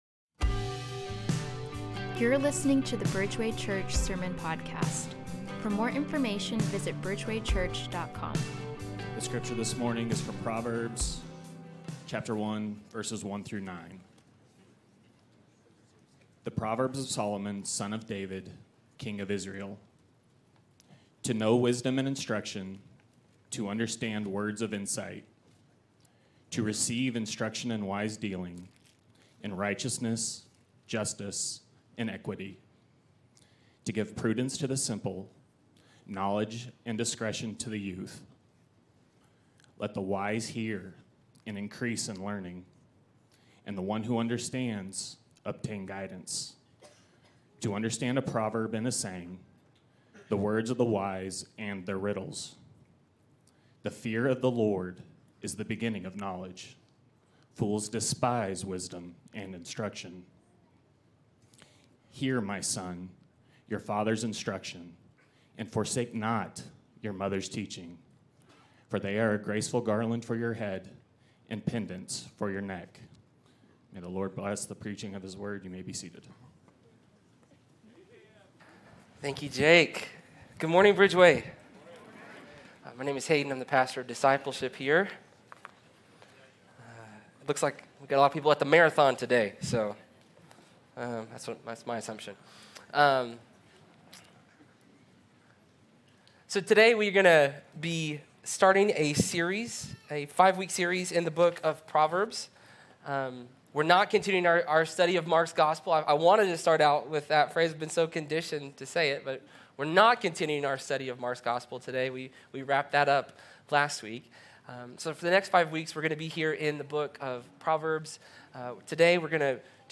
Bridgeway Church Sermons